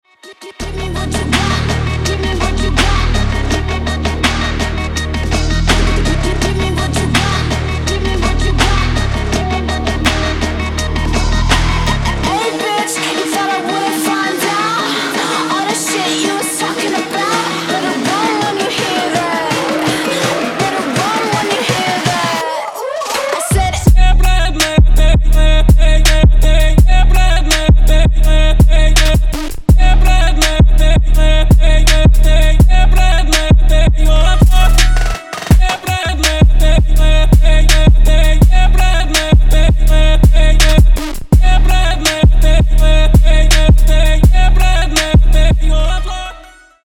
клубные , крутые , басы , восточные , bass house